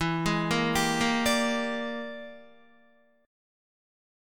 E7#9b5 Chord
Listen to E7#9b5 strummed